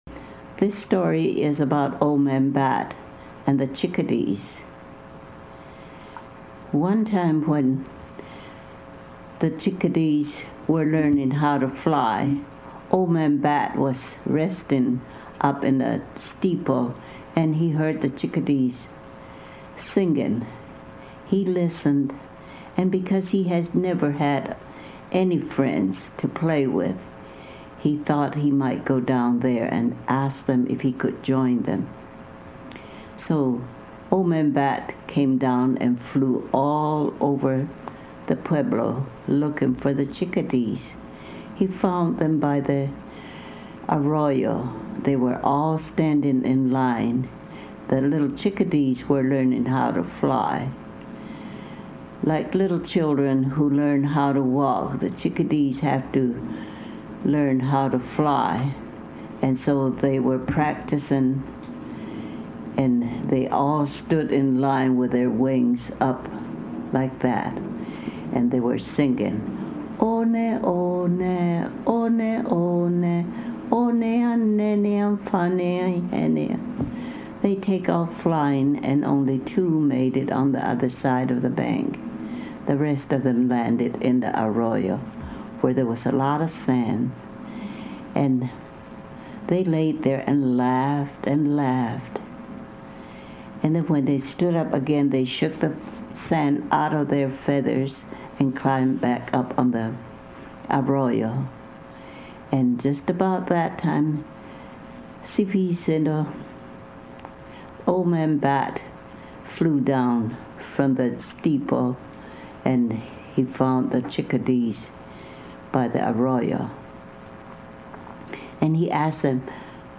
Stories
analog cassette tape recordings
original storytelling sessions
The accompanying CD contains seven stories, some matching exactly the text version of the story published in this book, and others that are a slightly different version told in a different setting than noted in the text.